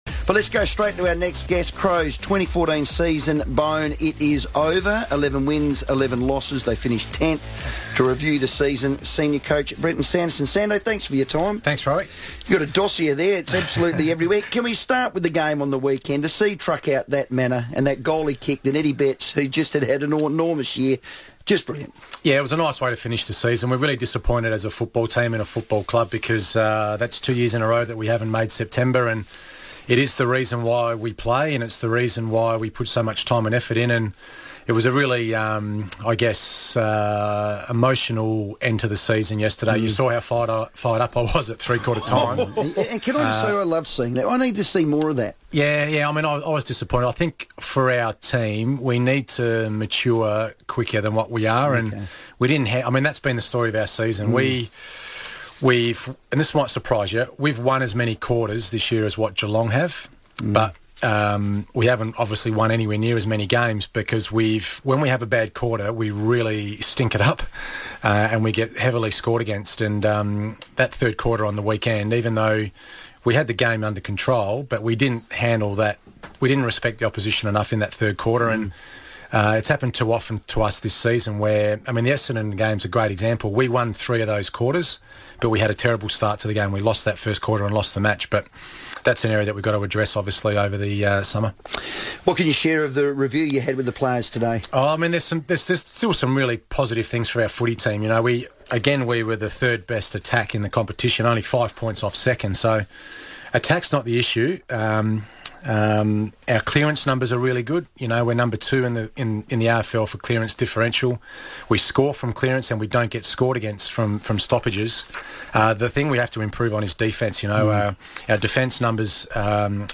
Sando wraps up the 2014 season in a special extended chat on the FIVEaa Sports Show